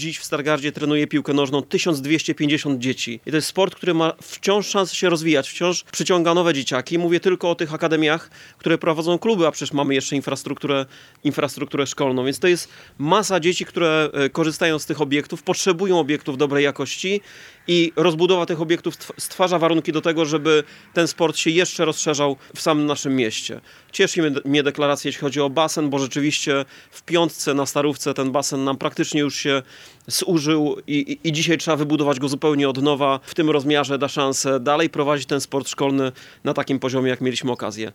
Prezydent Stargardu Rafał Zając przekazał podczas konferencji prasowej, że w Stargardzie piłkę nożną trenuje ponad 1000 dzieci, co za tym idzie rozbudowa i modernizacja infrastruktury jest niezbędna.